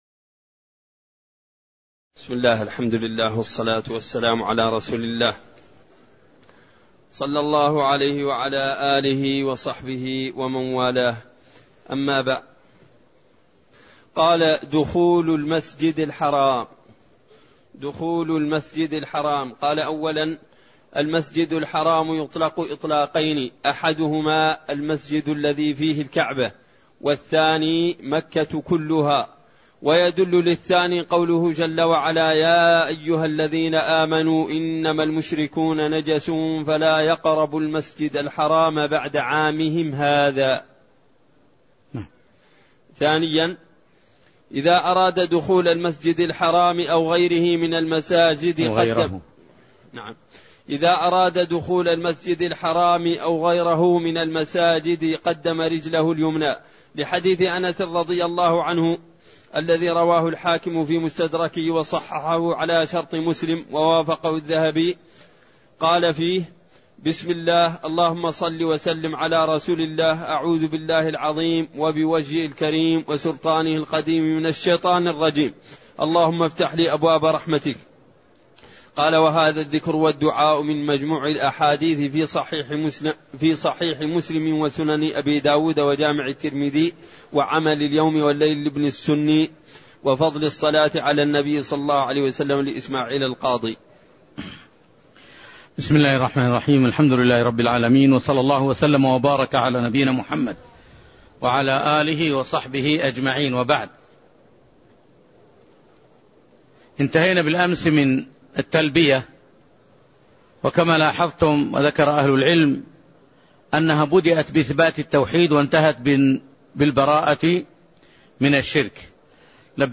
تاريخ النشر ١ ذو الحجة ١٤٣٠ هـ المكان: المسجد النبوي الشيخ